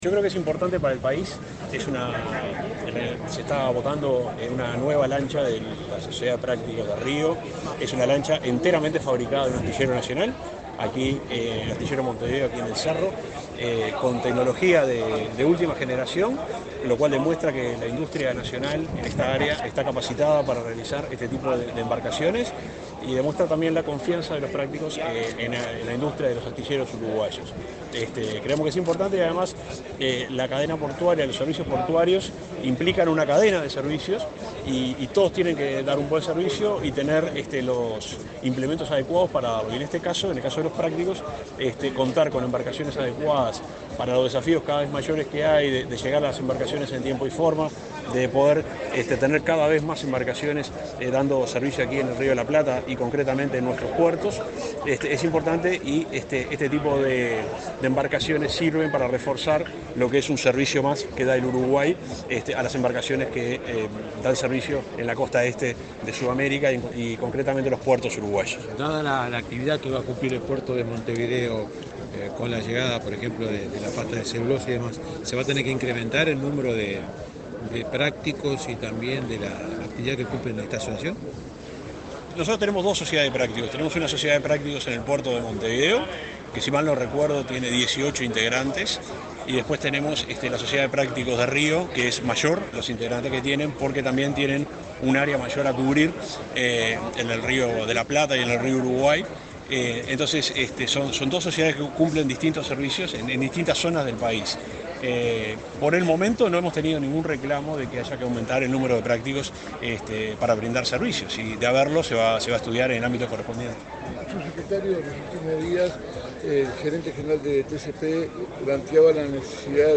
Declaraciones del subsecretario de Transporte
El subsecretario de Transporte, Juan José Olaizola, participó, junto con autoridades de la Administración Nacional de Puertos (ANP), en la ceremonia